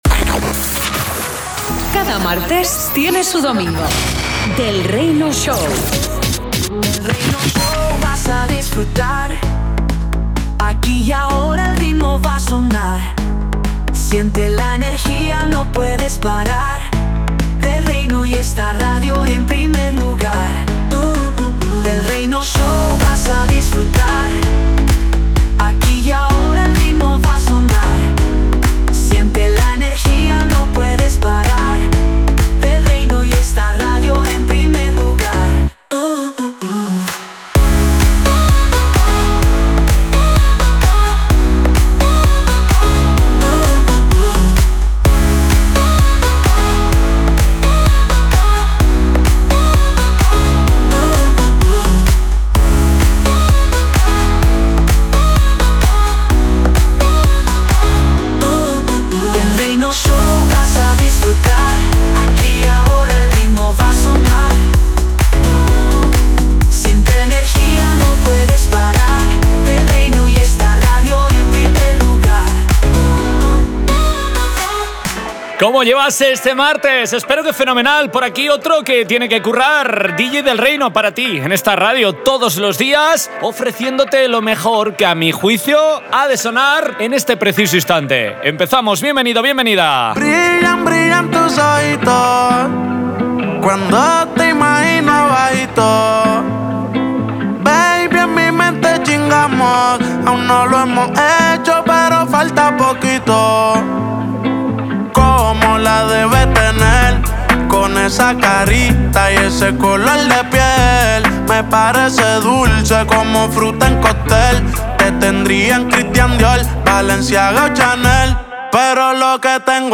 Del Reino Show un espacio nuevo con ritmo para acoger los mejores sonidos urbanos, latinos y dance más caliente. junto con remixes de los Nº1 del pop.DEMO DEL PODCASTProximamenteNecesitas el acceso Platinum para descargar.